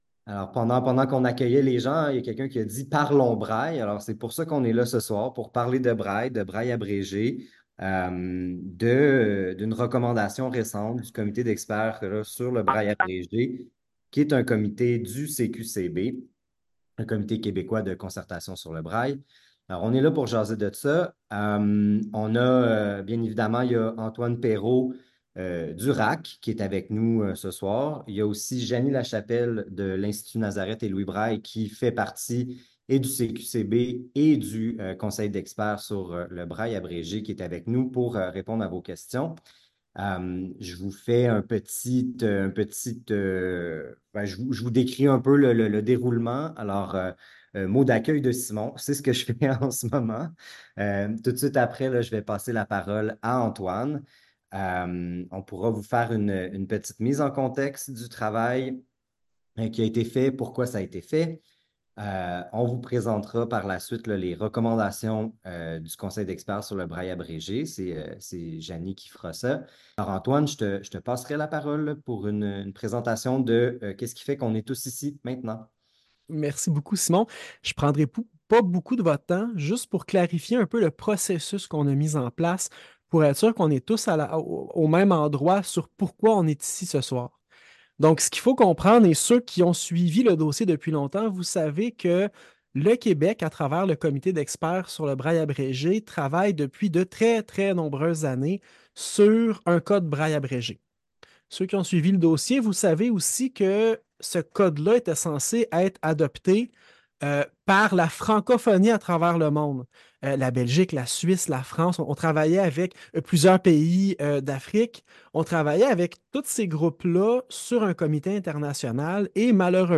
Le conseil d’administration du RAAQ souhaite maintenant consulter le milieu des personnes handicapées visuelles pour prendre une position éclairée par rapport aux recommandations du comité d’experts. Rencontre de présentation, recommandations du comité d’expert sur le braille abrégé Voici un enregistrement de la rencontre ayant eu lieu le 11 juin 2024.
rencontre-braille-abrégé.mp3